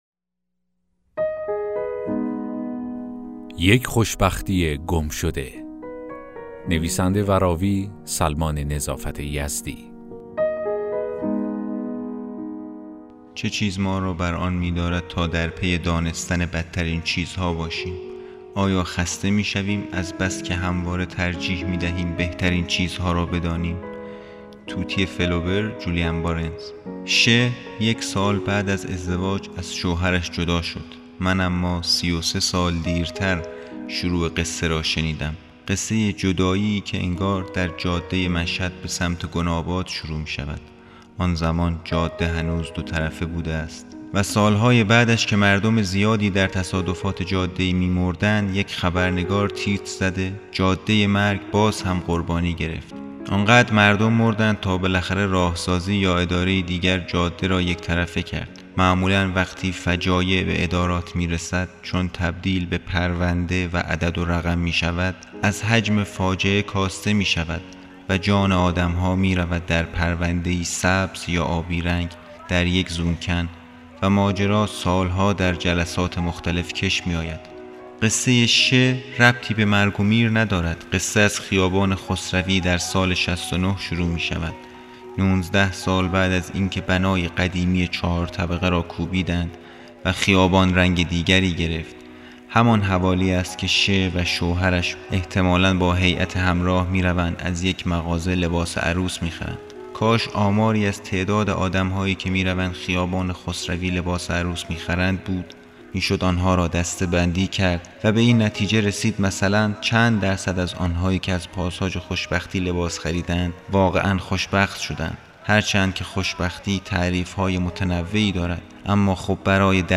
داستان صوتی: یک خوشبختی گم شده